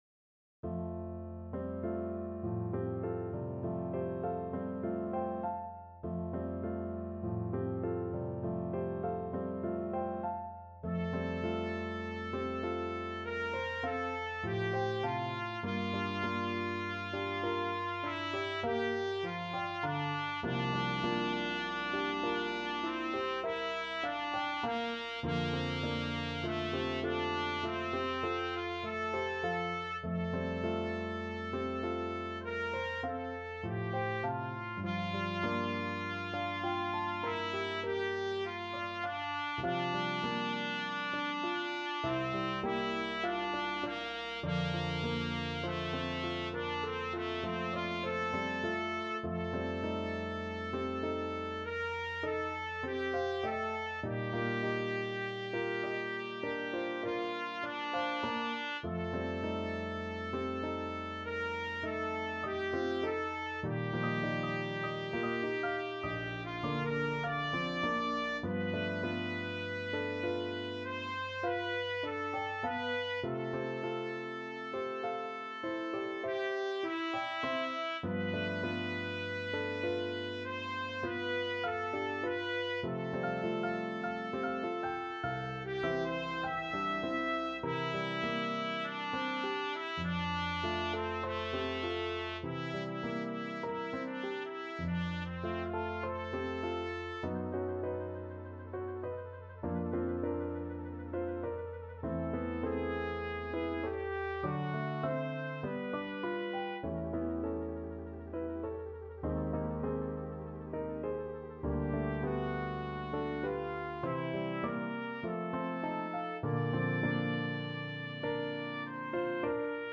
4/4 (View more 4/4 Music)
Lento =50
Classical (View more Classical Trumpet Music)